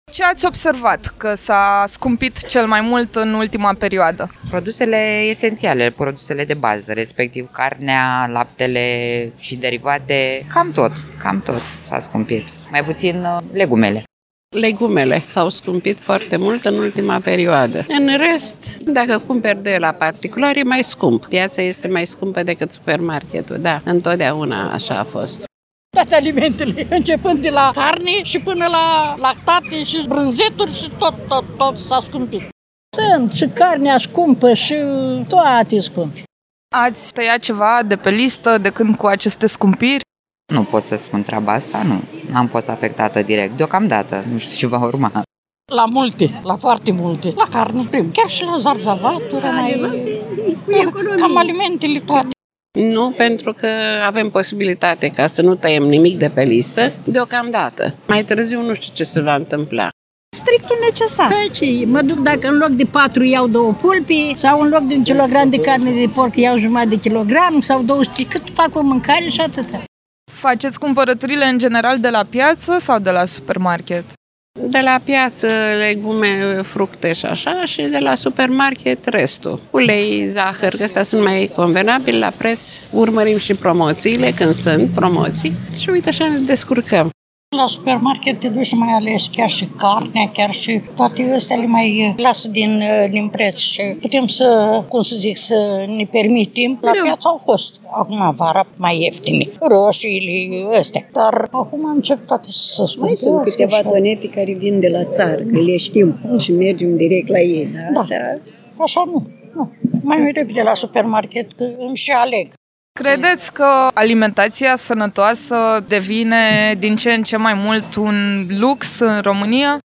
a mers în piața din Tulcea să afle ce s-a scumpit cel mai mult, cum se descurcă oamenii și cât de accesibilă mai e hrana sănătoasă pentru români.